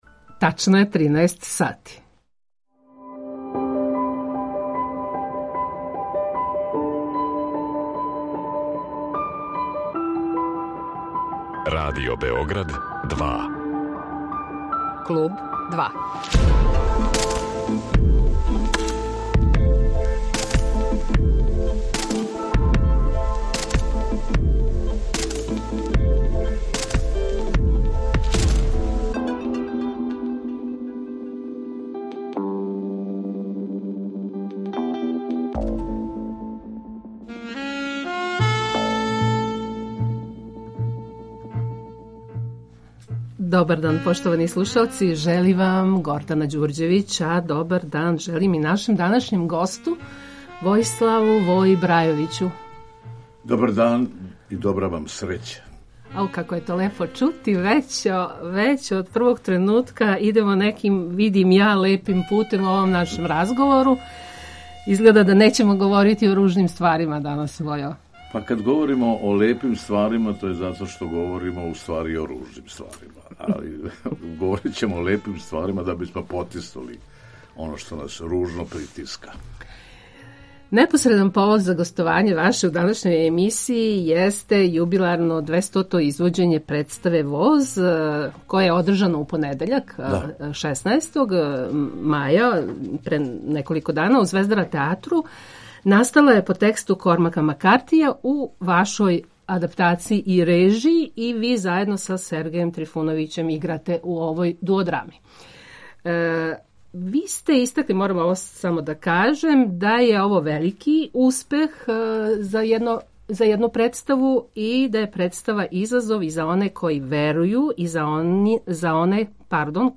Гост Клуба 2 је један од наших најзначајнијих глумаца и дугогодишњи председник Удружења драмских уметника Србије Војислав Брајовић.